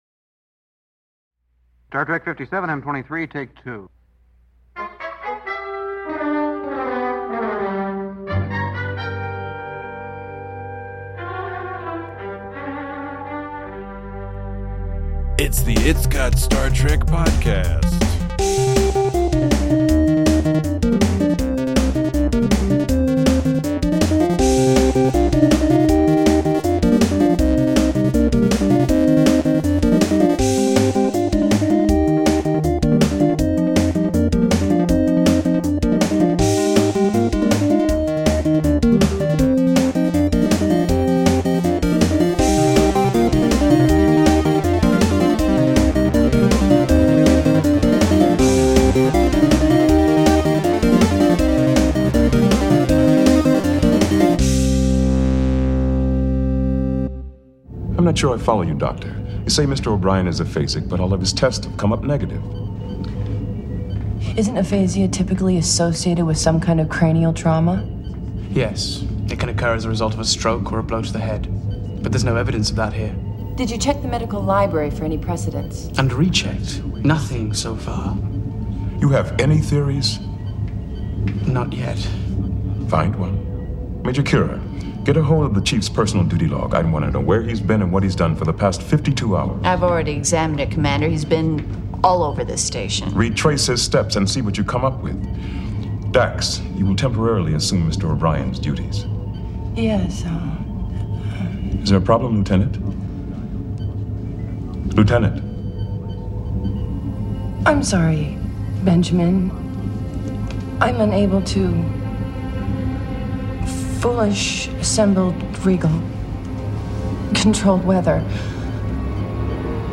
Join your gabble-friendly hosts as they discuss early season one characterizations that stand the test of time and also a bunch of other stuff that is sure to interest and entertain you.